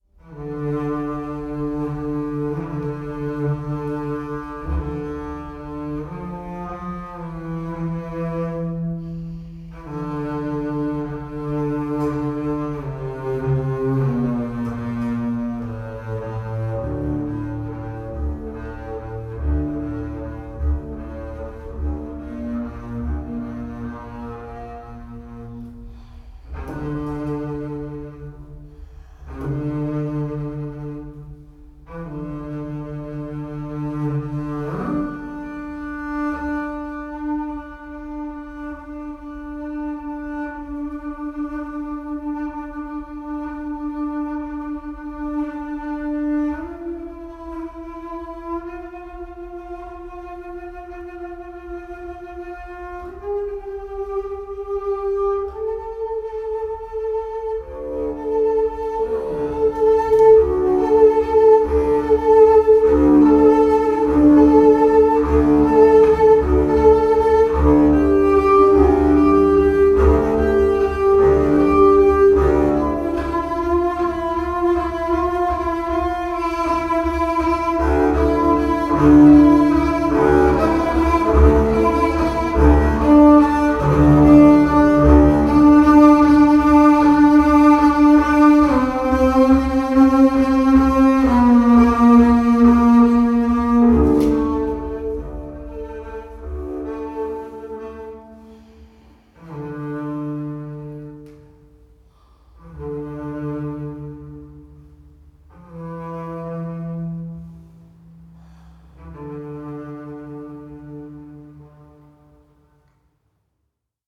double bass, voice